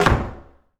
door_close_slam_03.wav